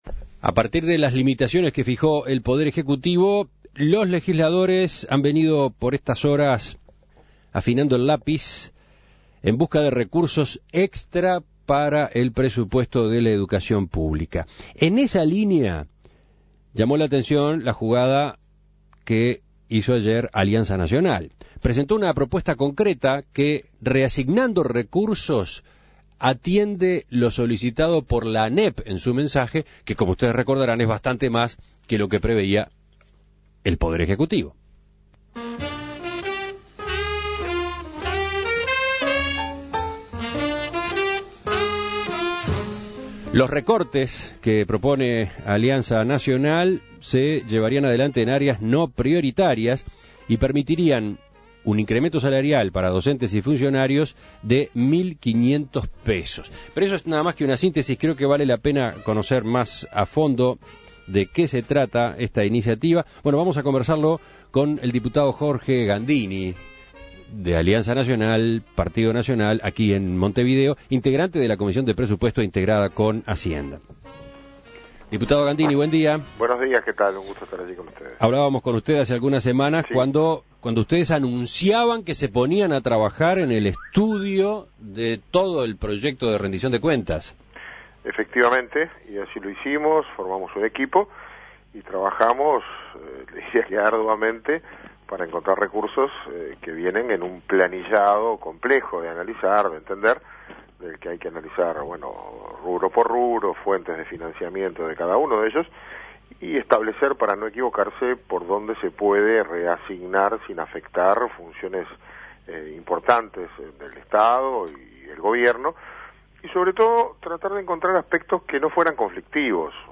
Escuche la entrevista a Jorge Gandini